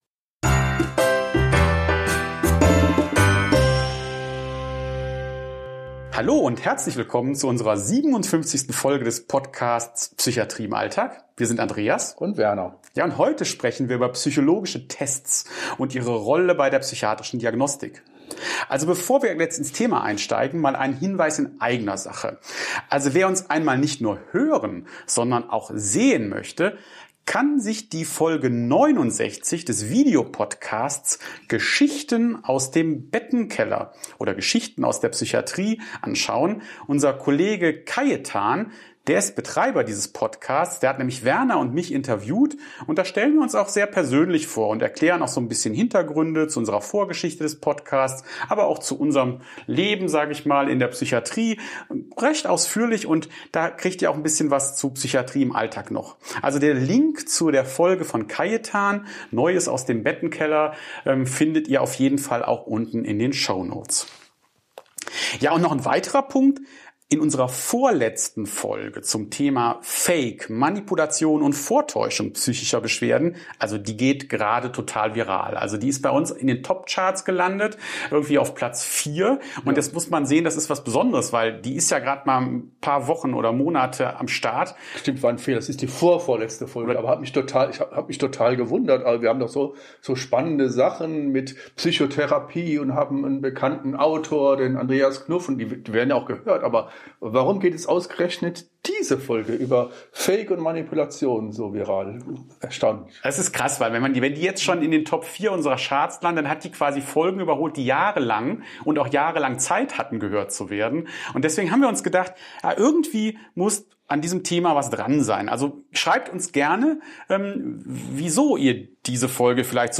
Die beiden Gastgeber tauchen tief in die Materie ein: Was macht einen Test wirklich psychometrisch fundiert?